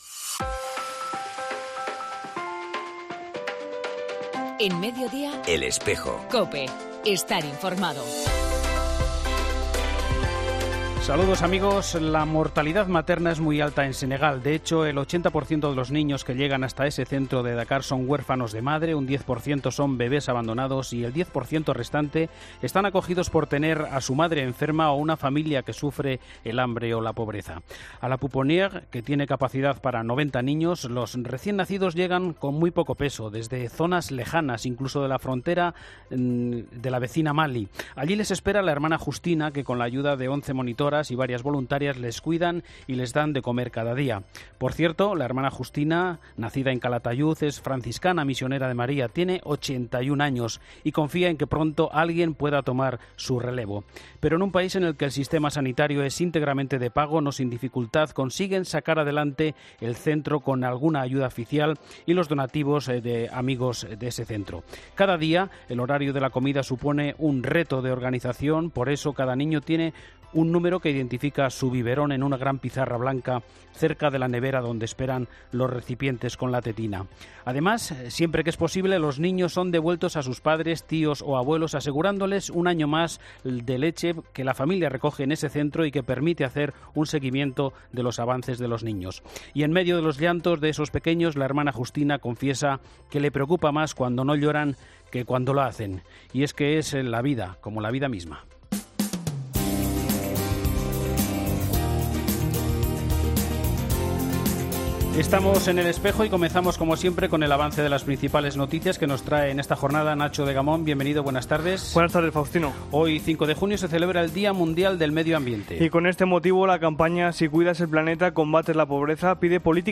En El Espejo del 5 de junio entrevistamos al misionero burgalés